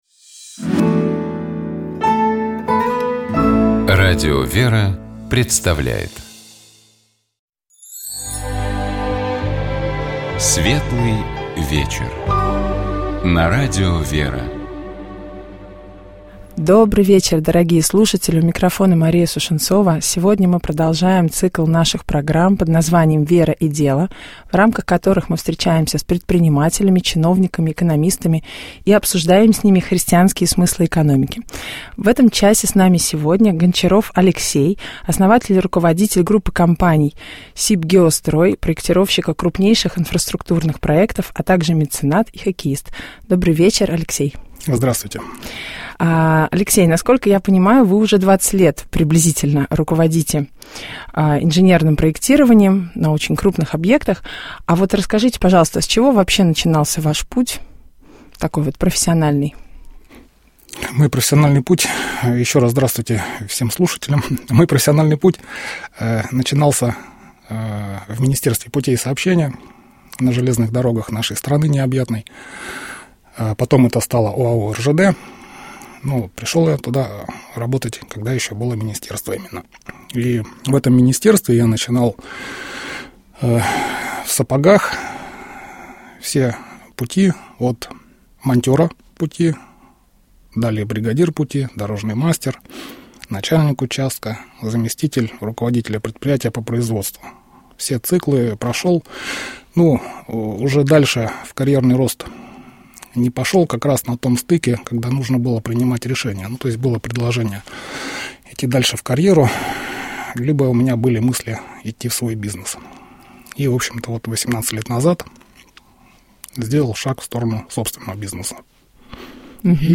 Программа «Светлый вечер» — это душевная беседа ведущих и гостей в студии Радио ВЕРА.